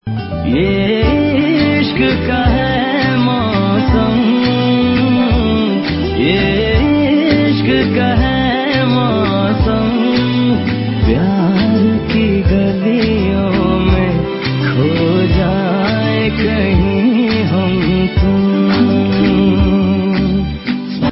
Bollywood - Hindi